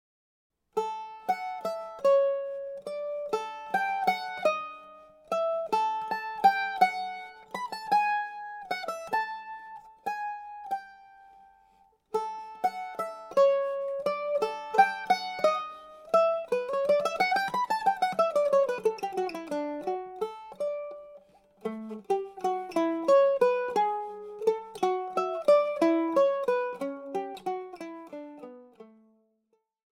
Recueil pour Mandoline